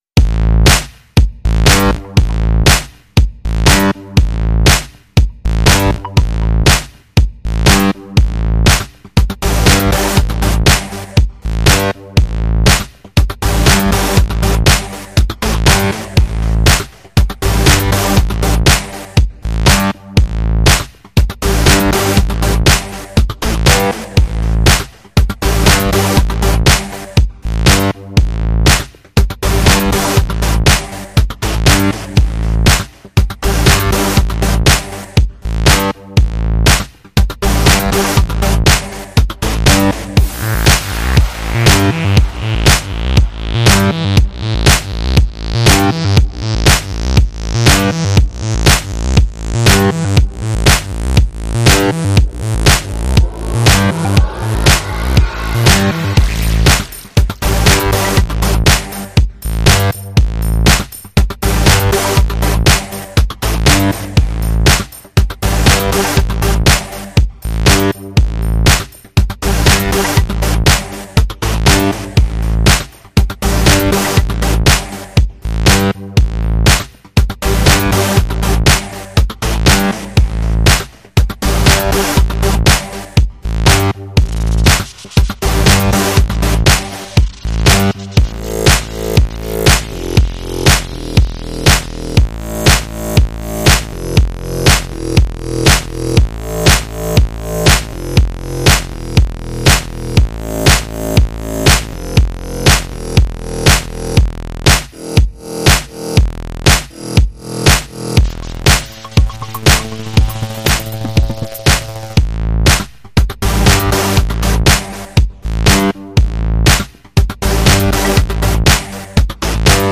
это завораживающая композиция в жанре инди-поп